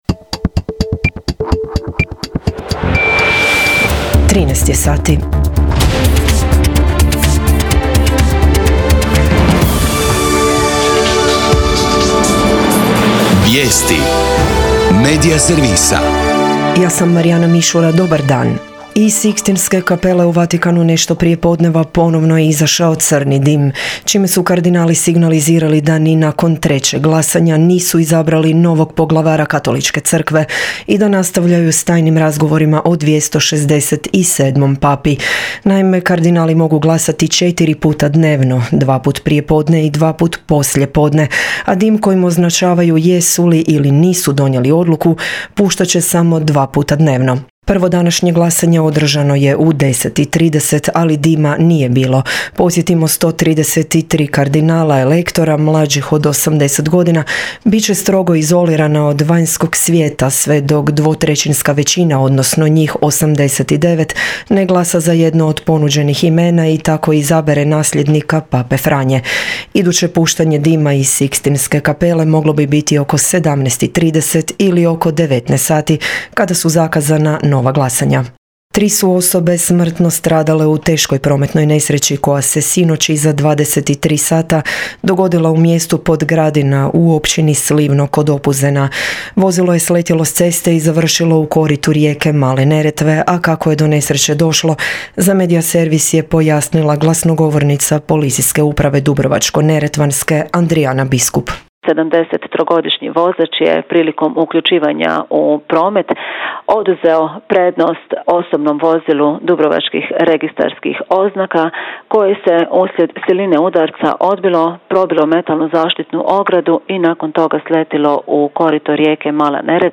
VIJESTI U 13